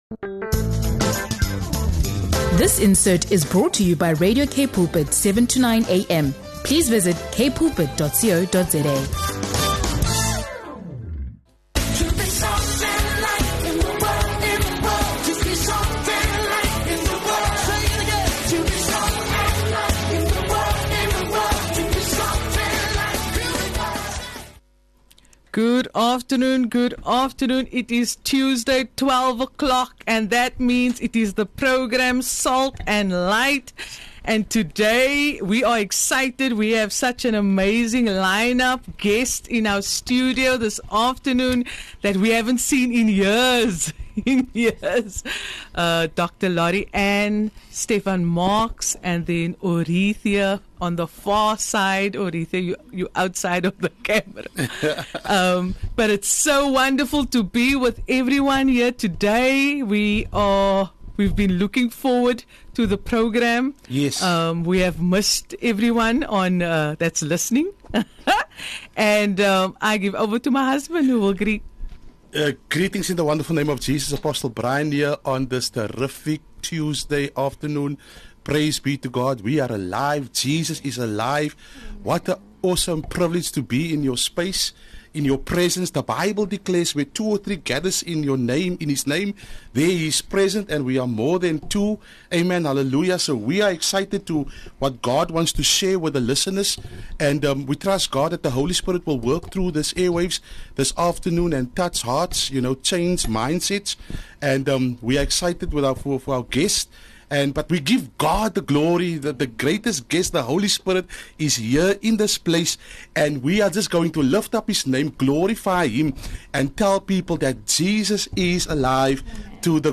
Tune in for an inspiring conversation on faith, restoration, and the power of hope in action.